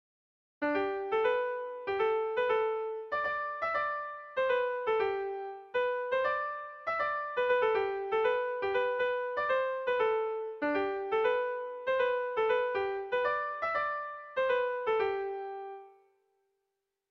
Sentimenduzkoa
ABA